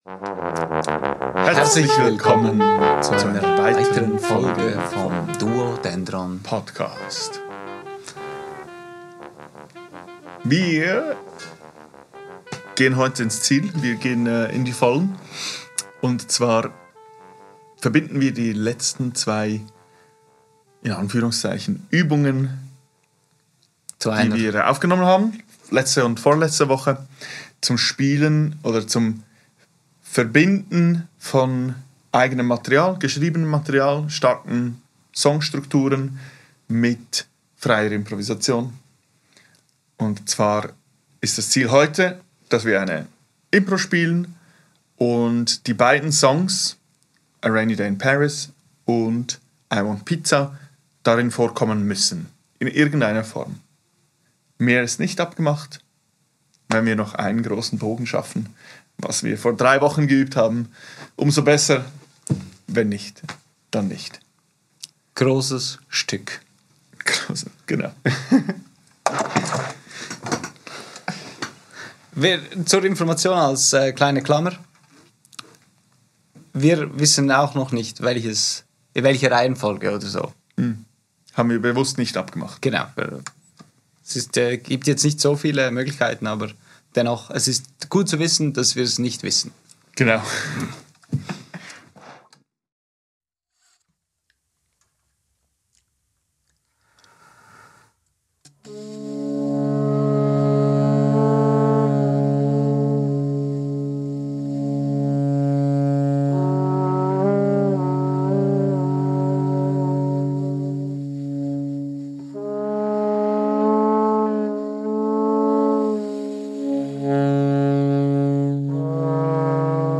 Aufgenommen am 20.08.2024 im Atelier